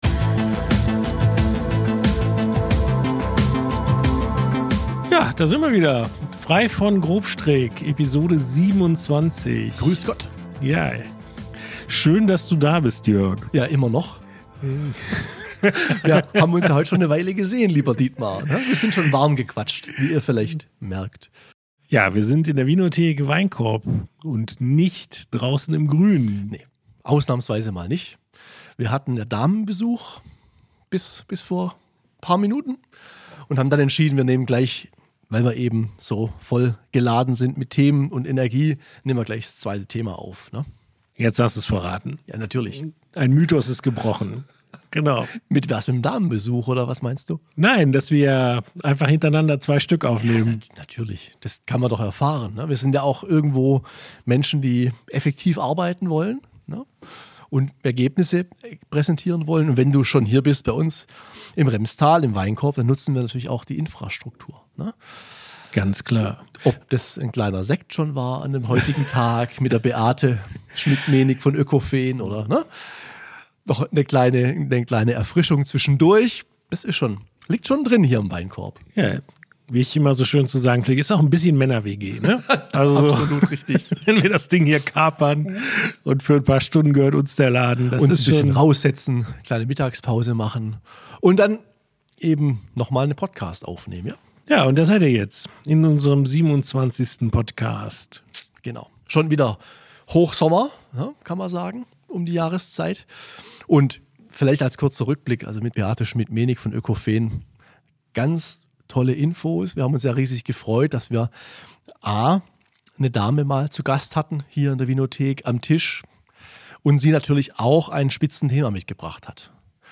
Locker bis launig, meinungsstark und informativ, das ist „Frei von Grobstrick“, der HeizungsJournal-Podcast.